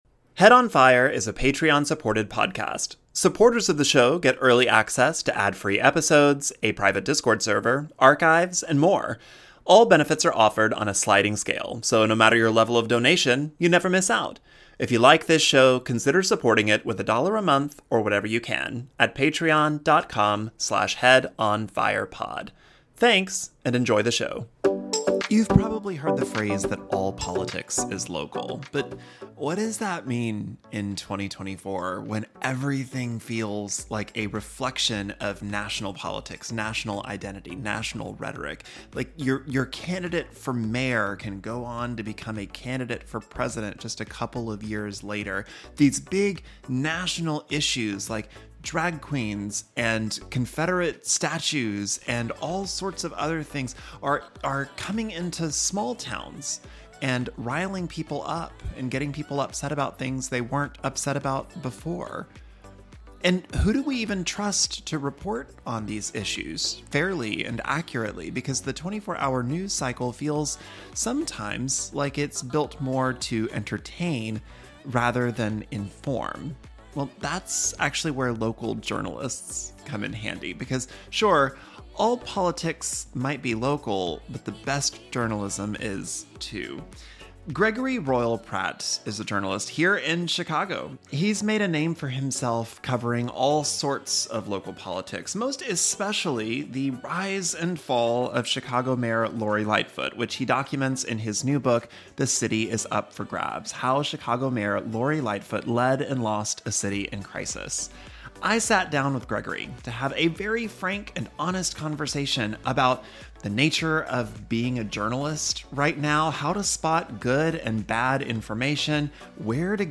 We had a conversation about the power of local journalism, the influence of national politics in small town spaces, and why he wrote a book about Lori Lightfoot.